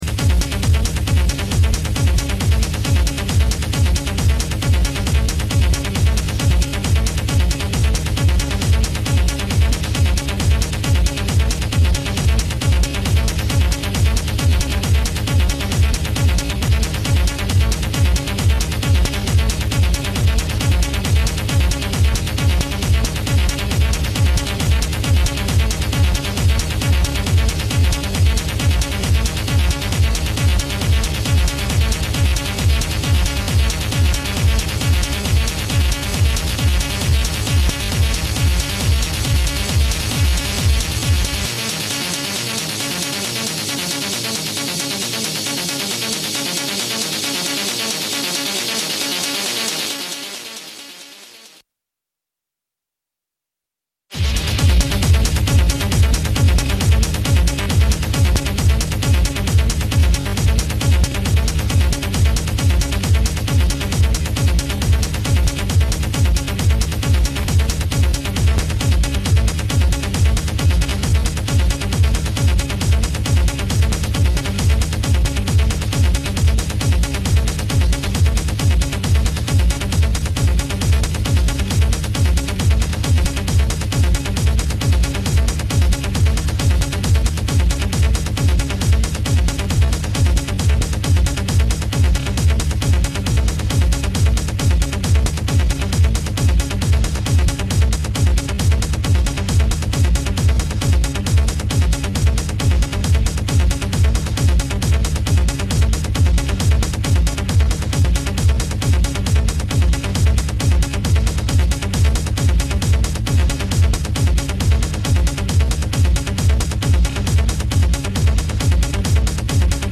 Sendung für elektronische Musik Dein Browser kann kein HTML5-Audio.
Das Spektrum der musikalischen Bandbreite reicht von EBM , Minimalelektronik, Wave ,Underground 80`s bis hin zu Electro ,Goth und Industriell.